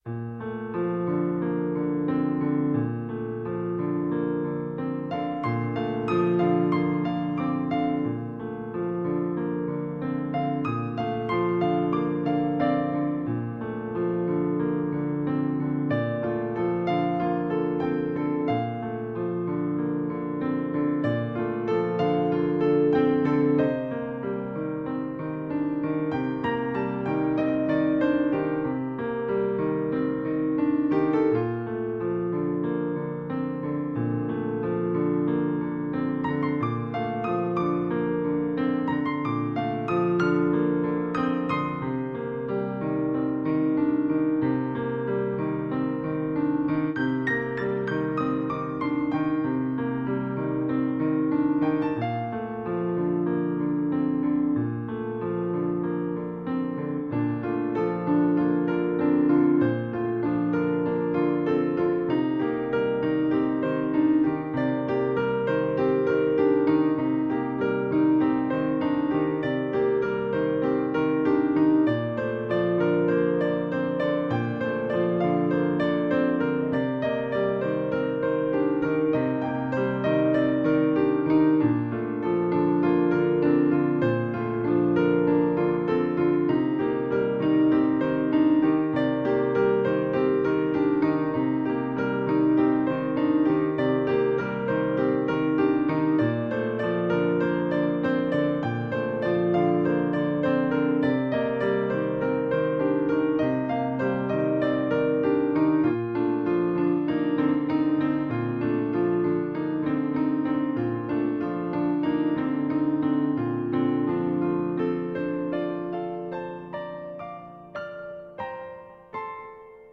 Solo Instrument